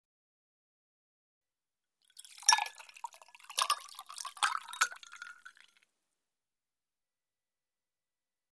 Звуки льда
Звук наливания жидкости в стакан со льдом